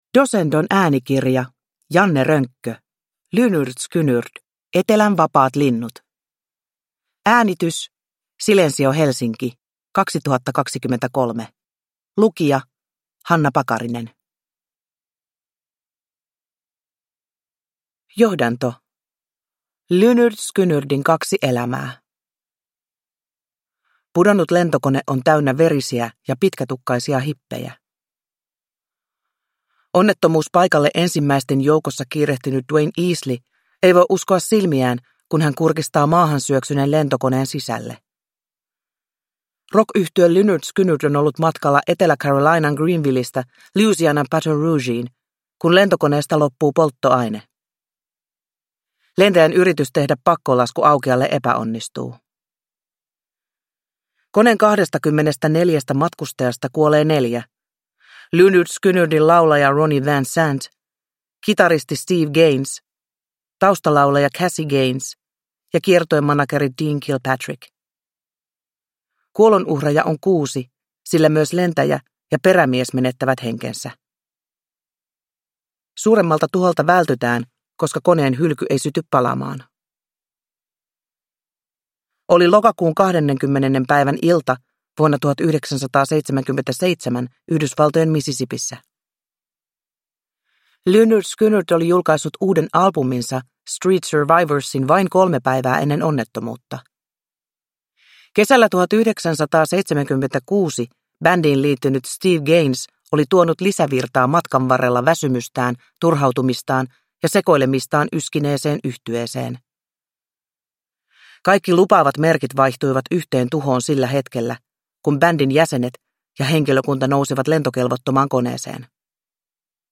Lynyrd Skynyrd – Ljudbok – Laddas ner
Uppläsare: Hanna Pakarinen